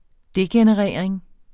Udtale [ ˈdegenəˌʁεˀɐ̯eŋ ]